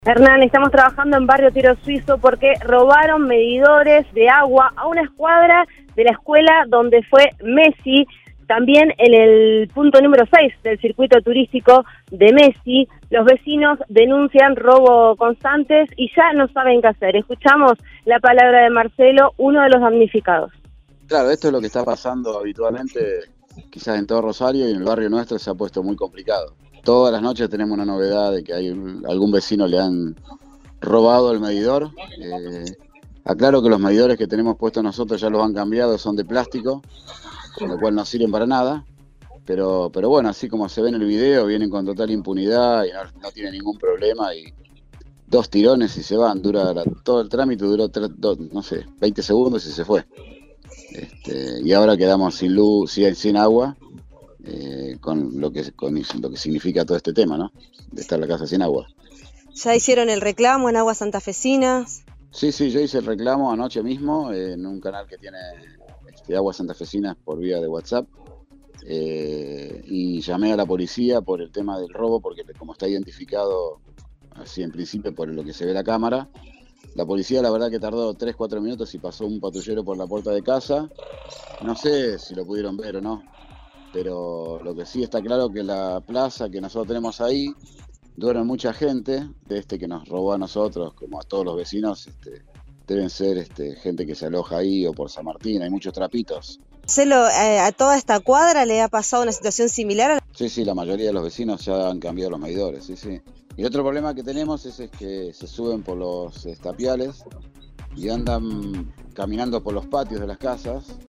uno de los damnificados, habló con el móvil de Cadena 3 Rosario, en Siempre Juntos